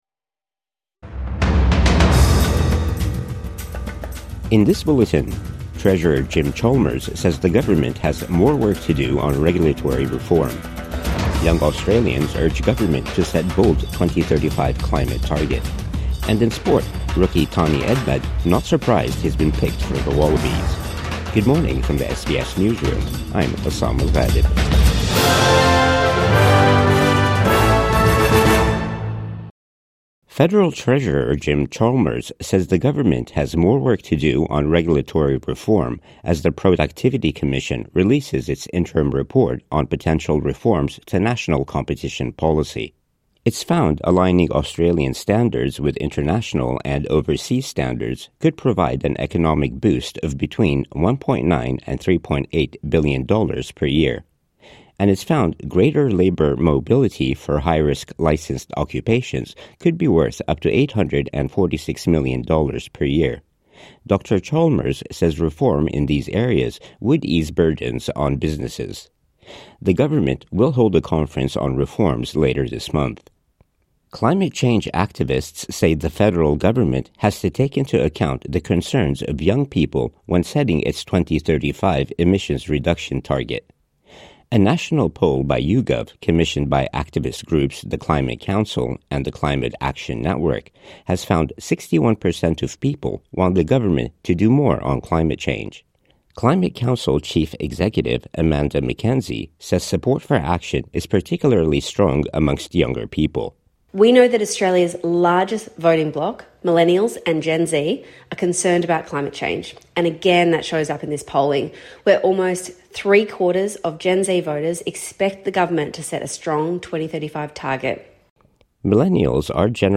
Jim Chalmers says government has more work to do on regulatory reform | Morning News Bulletin 8 August 2025 | SBS News